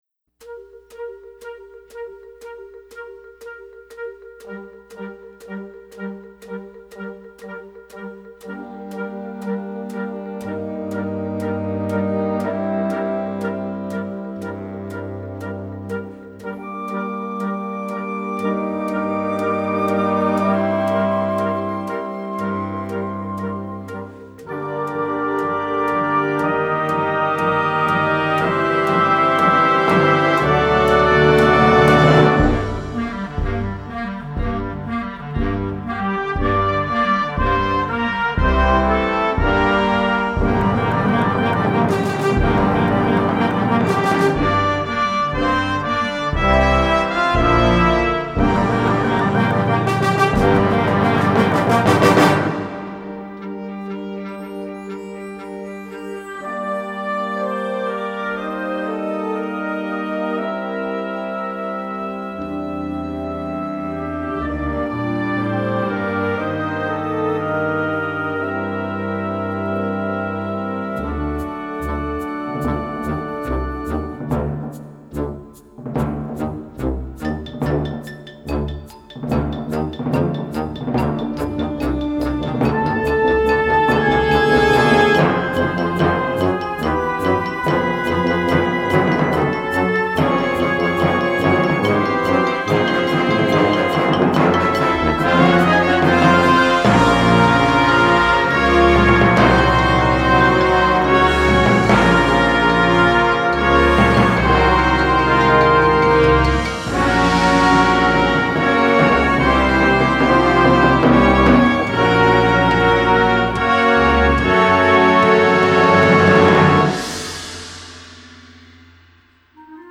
Besetzung: Blasorchester
düster-bezaubernde Filmmusik
ein ausgewogenes Medley